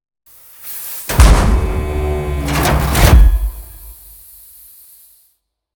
scrape1.ogg